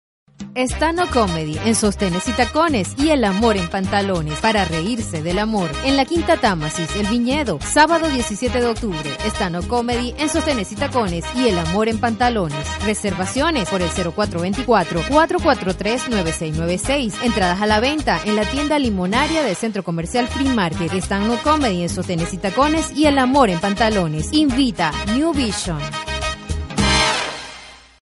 Voz joven, experiencia en grabación de comerciales, voz en off, contestadoras empresariales, programas de radio y animación en vivo
Sprechprobe: Werbung (Muttersprache):
Young voice, experience in commercial recording, voice over, business answering, radio programs and live animation